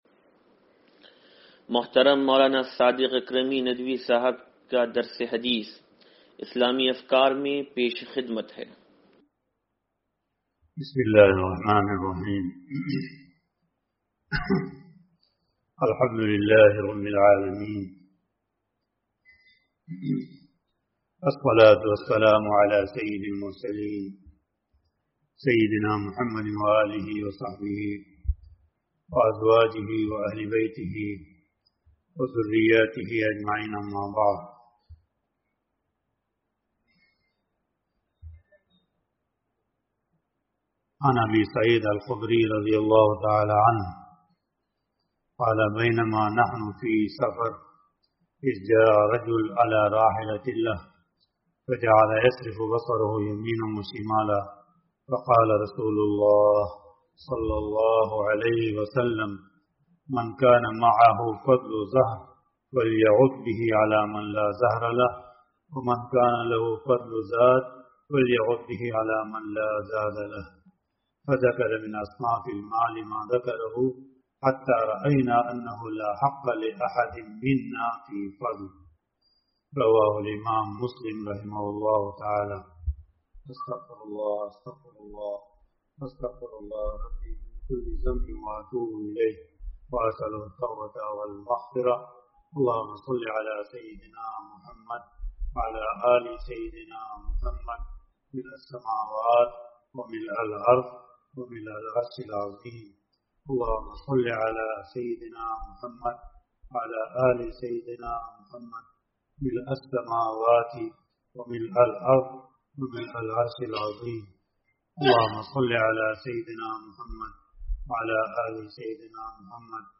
درس حدیث نمبر 0757